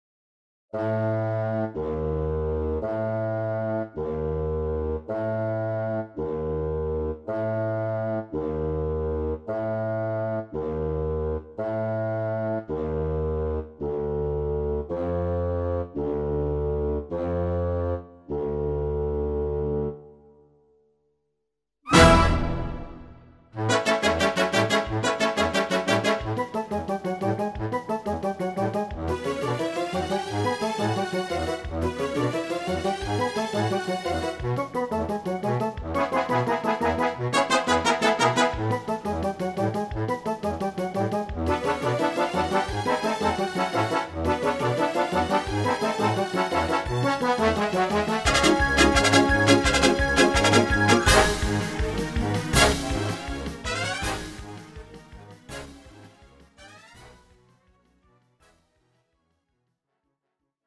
음정 (-1키)
장르 뮤지컬 구분